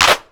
Clap26.wav